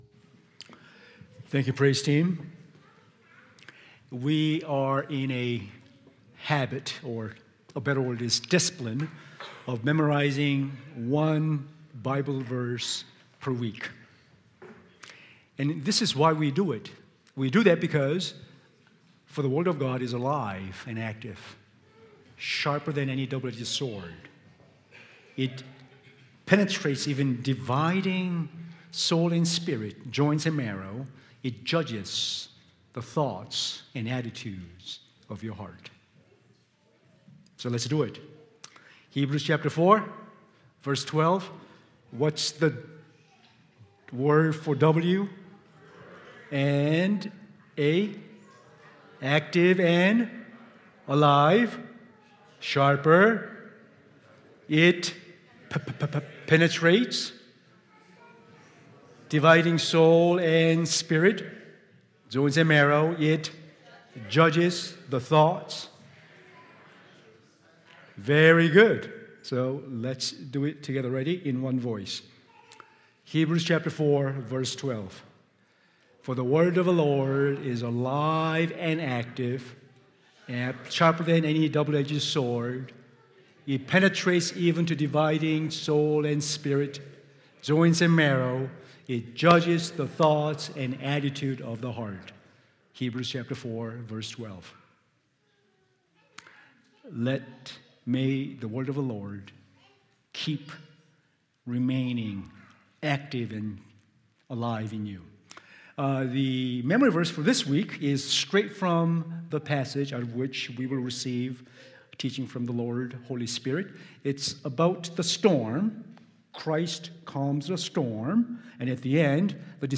Current Sermon Trusting the One Who Commands the Storm Jesus on the Move, will you follow?